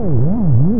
cloud.ogg